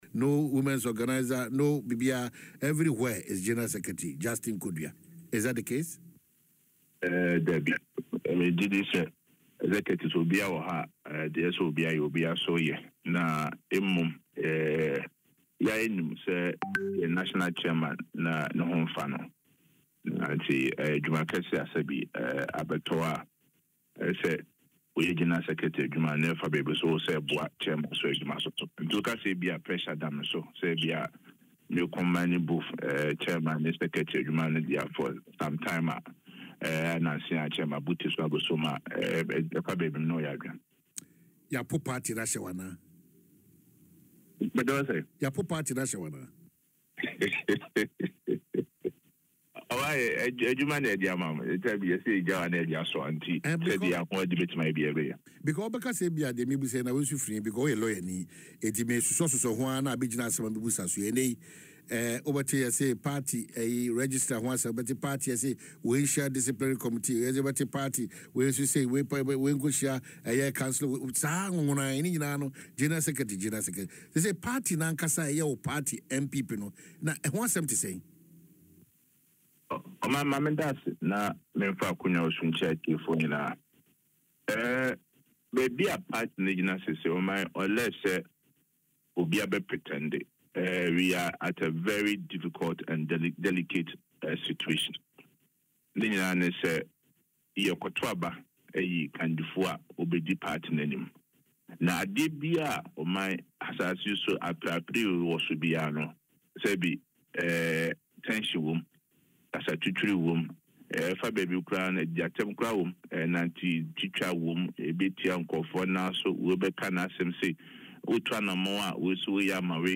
Speaking in an interview on Adom FM’s Dwaso Nsem, Mr. Kodua emphasized that the party’s current state cannot be downplayed.